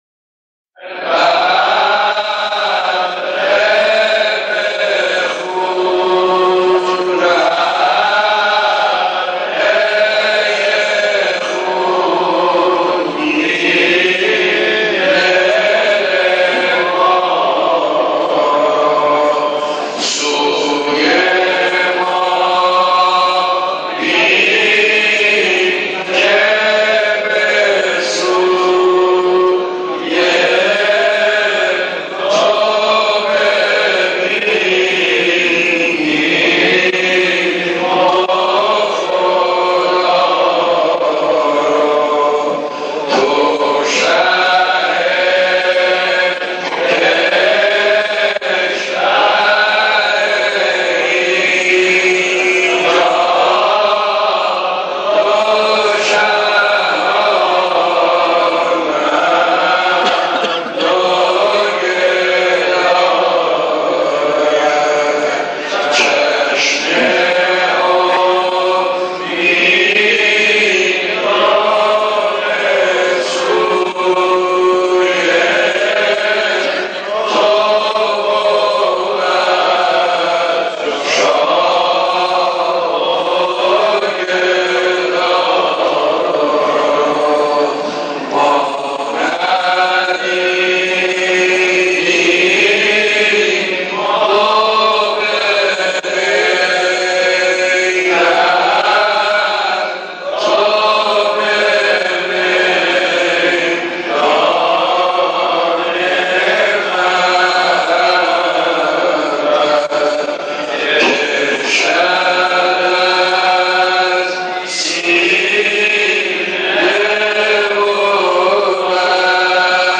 نوحه‌خوانی خطاب به حضرت اباعبدالله (ع)